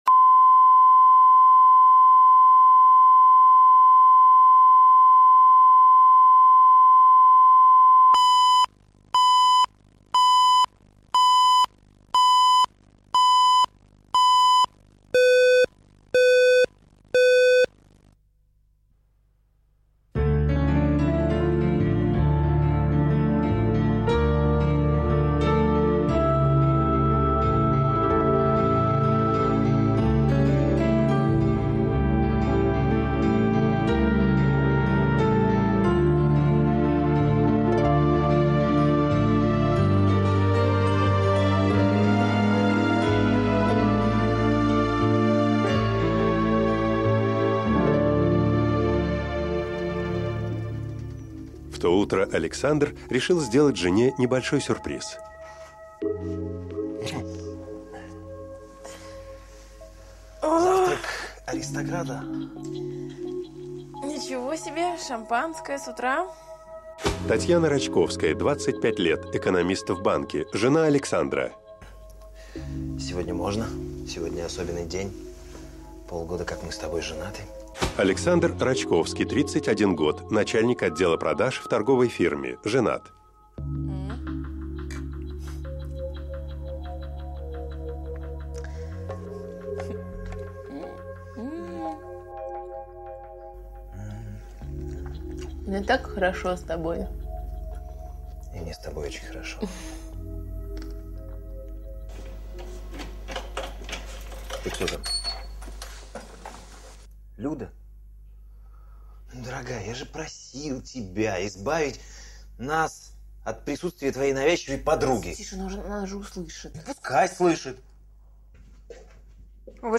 Аудиокнига С барского плеча